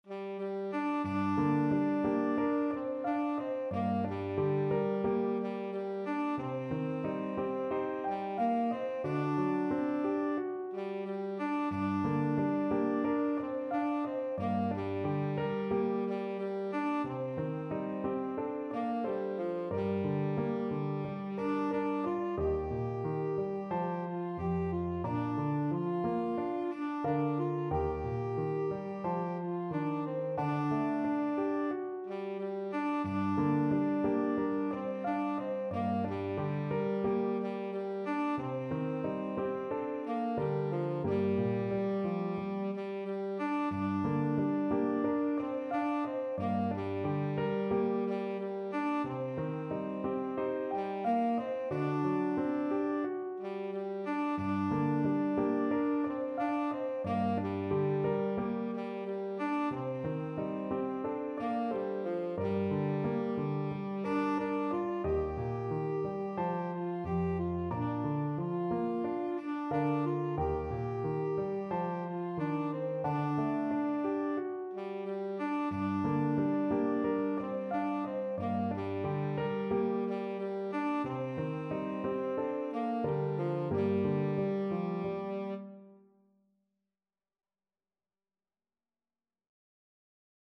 F4-G5
4/4 (View more 4/4 Music)
Andante = c. 90